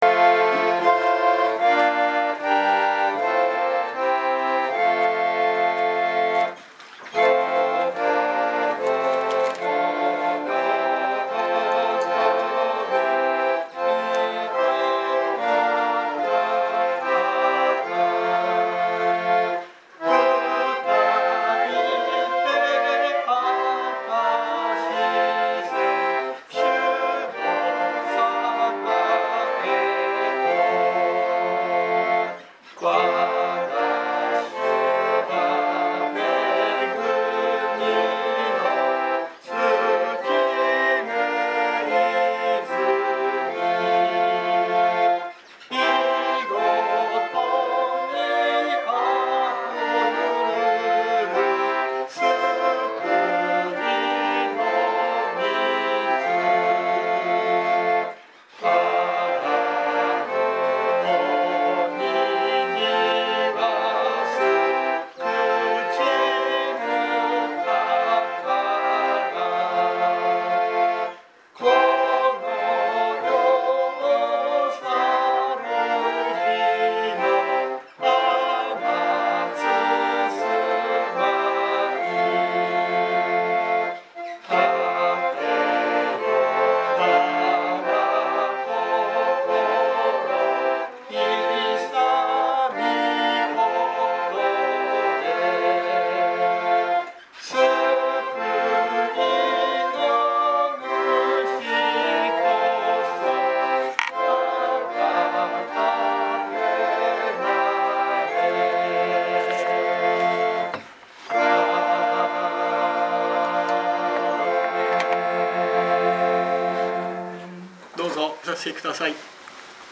2023年11月26日朝の礼拝「悲しみは喜びへ」熊本教会
説教アーカイブ。